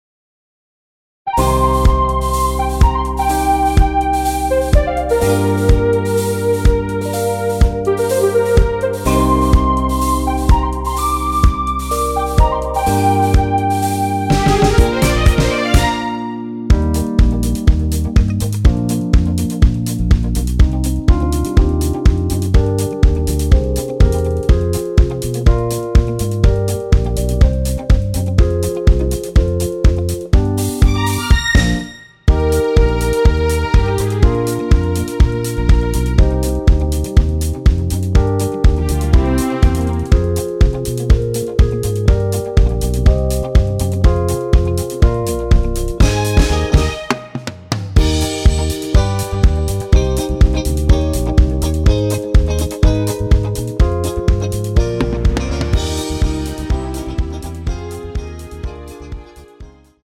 원키에서(+3)올린 MR입니다.
Eb
앞부분30초, 뒷부분30초씩 편집해서 올려 드리고 있습니다.
중간에 음이 끈어지고 다시 나오는 이유는